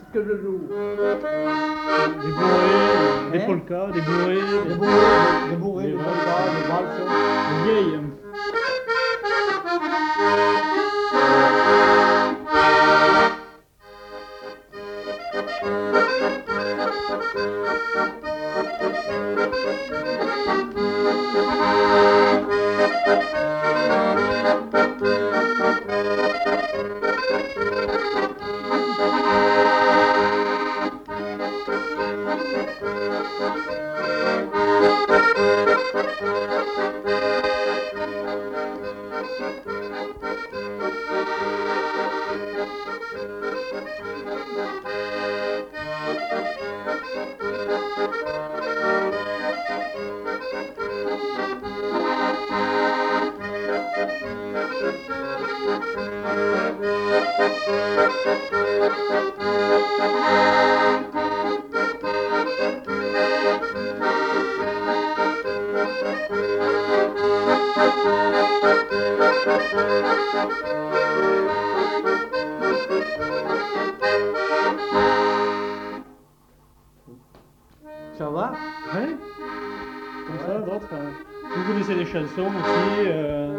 Bourrée